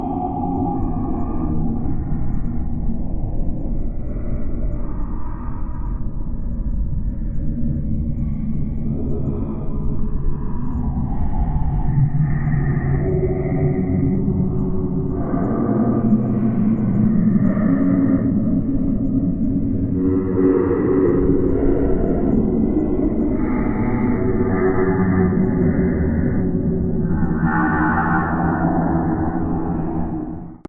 一小时不间断的恐怖声音和背景氛围的万圣节
描述：万圣节的声音和背景氛围包括1小时的狼人嚎叫，女巫嘎嘎叫，女人和男人尖叫，以及万圣节期待你会听到的其他令人毛骨悚然的声音。